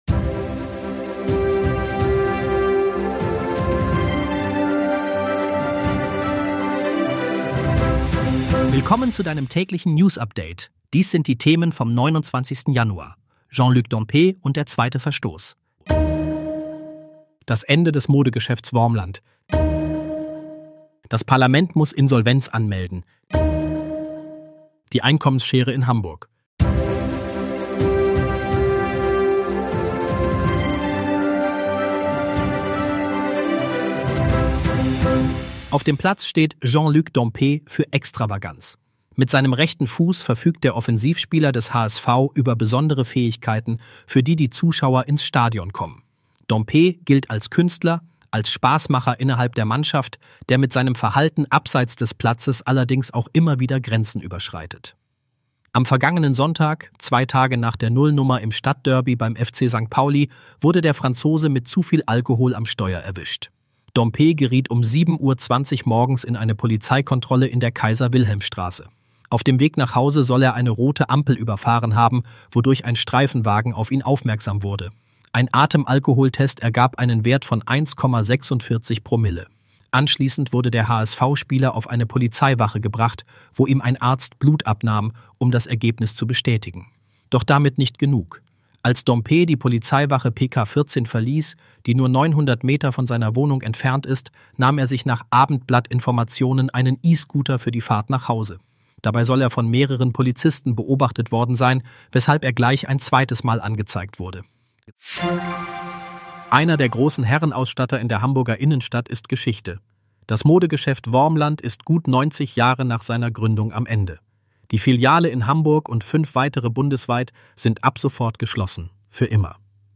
Hamburg-News - der aktuelle Nachrichten-Überblick um 17 h